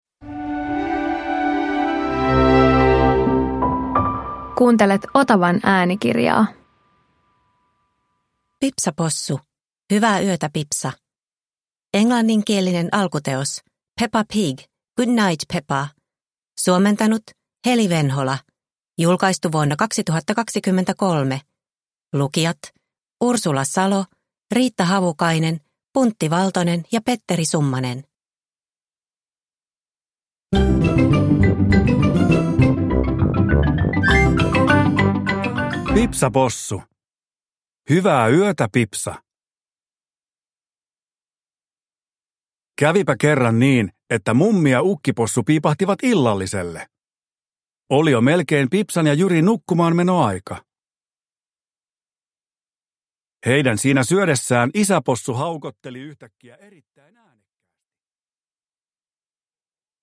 Pipsa Possu - Hyvää yötä, Pipsa! – Ljudbok – Laddas ner